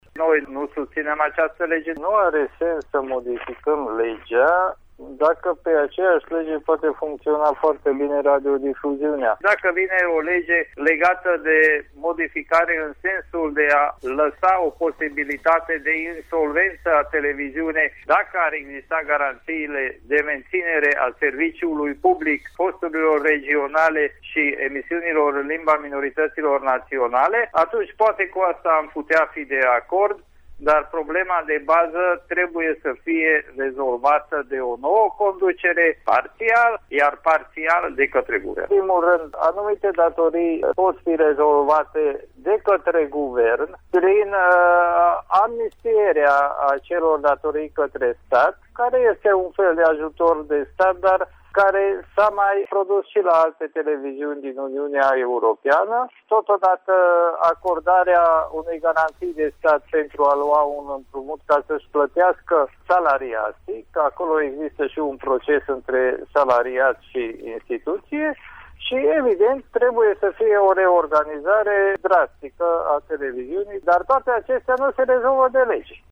Proiectul de modificare a Legii 41/1994 – care reglementează organizarea și funcţionarea Societăţilor Române de Radio şi de Televiziune – a fost subiectul dezbaterii în cadrul emisiunii ”Pulsul zilei” de marți, 10 martie 2016, difuzată la Radio România Oltenia Craiova.
În cadrul discuțiilor, deputatul Márton Árpád a declarat că parlamentarii UDMR nu vor susține această inițiativă legislativă și că implicarea Guvernului în soluționarea crizei din TVR este decisivă: